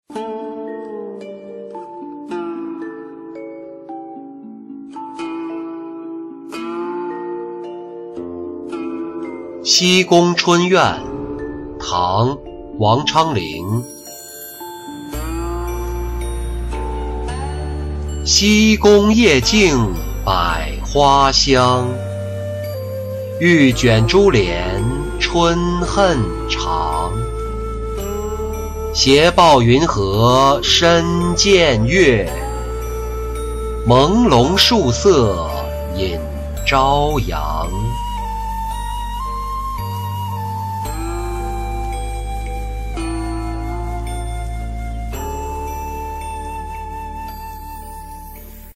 西宫春怨-音频朗读